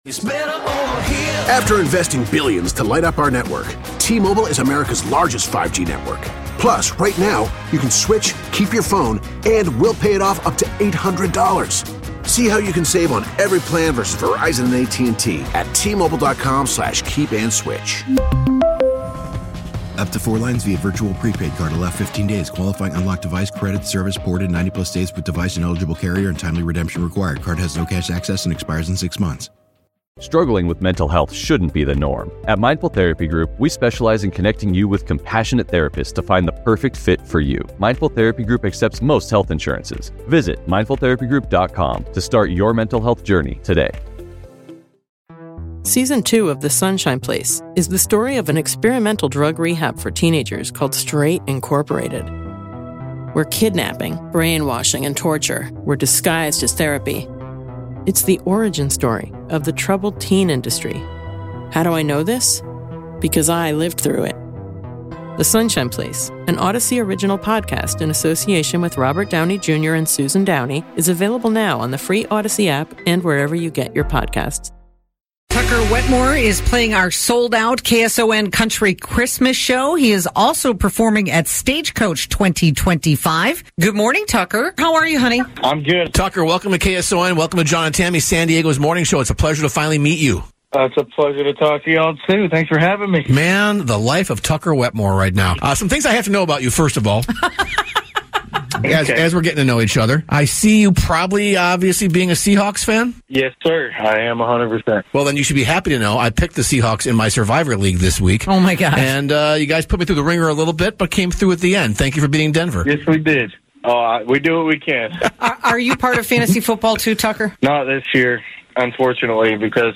We chatted with Tucker this morning about playing Stagecoach and (maybe more importantly) playing KSON's Country Christmas in December!